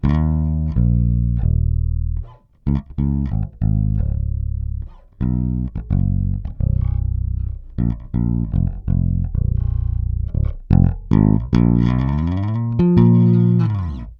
Takhle hraje můj JB (pasiv) při podladění o 1/2 tón. Struny ocel, staroba (několik let).
prsty